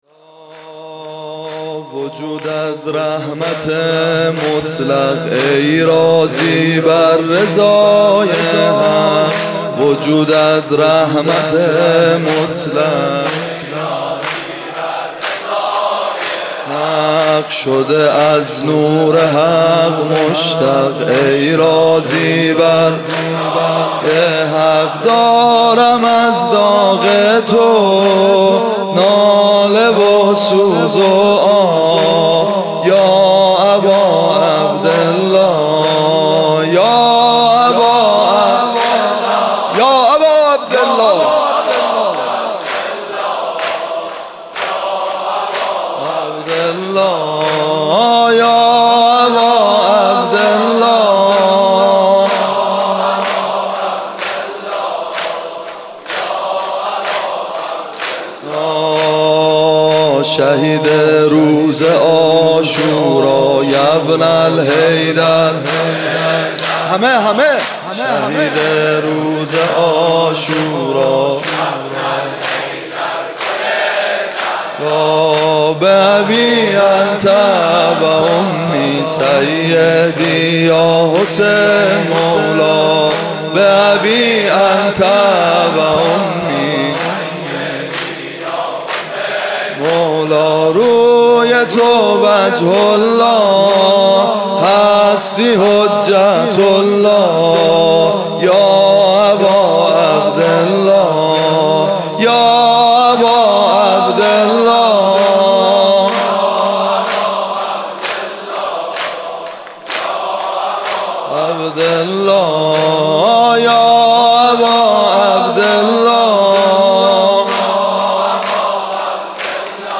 شب دوم ماه محرم95/هیت ابن الرضا(ع)